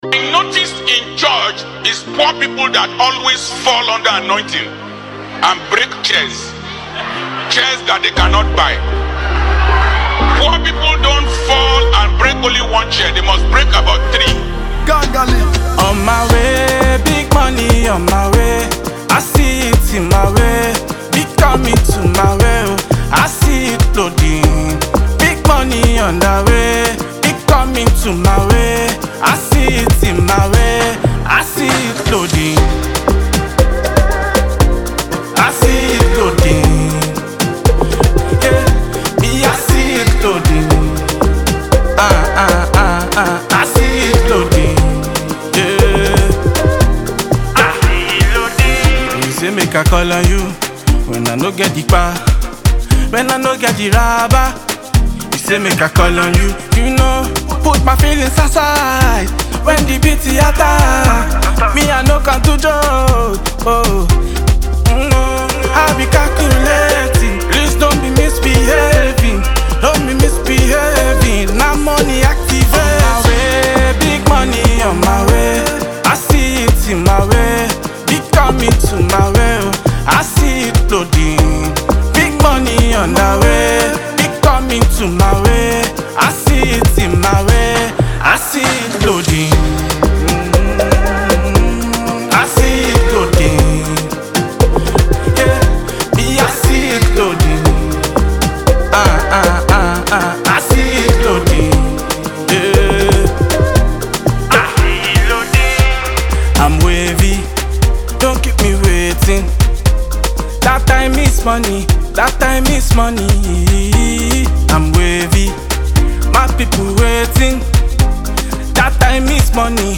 has a strong Afrobeat vibe and lyrics that highlight hustle